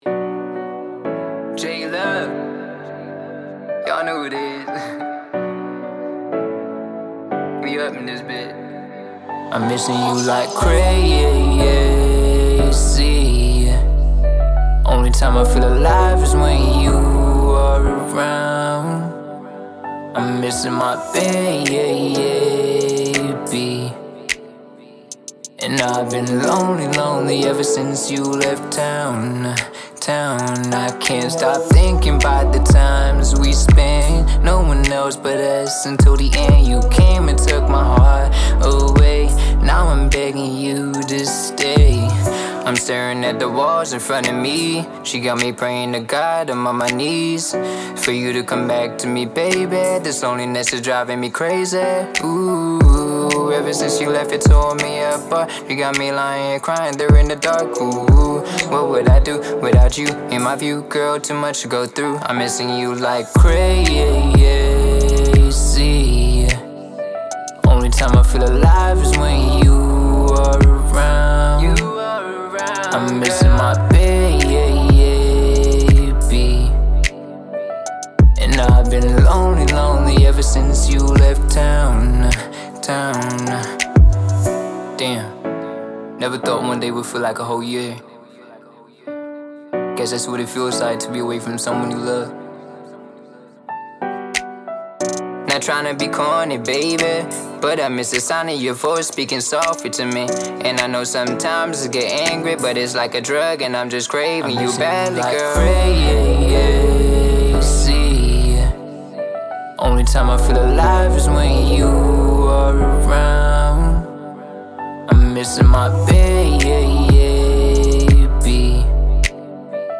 Song Writing and Recording
Singer/Songwriter